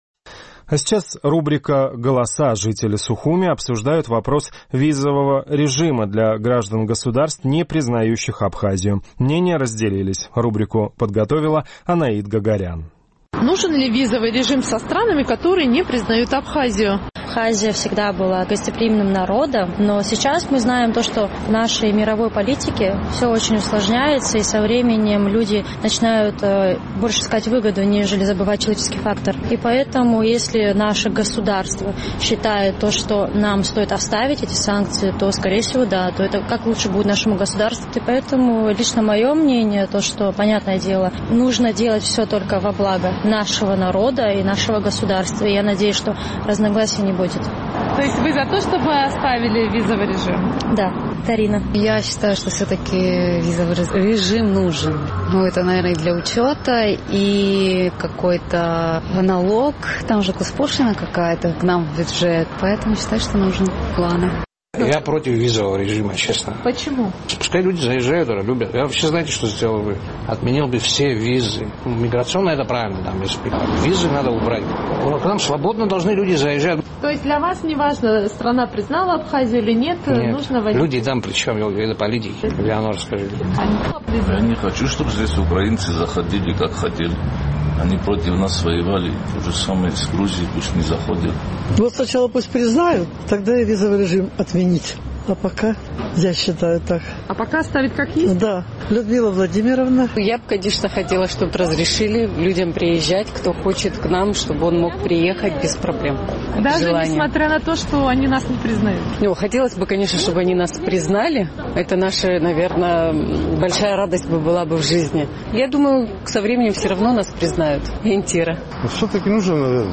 Наш сухумский корреспондент поинтересовалась у жителей абхазской столицы, нужен ли визовый режим со странами, которые не признают Абхазию.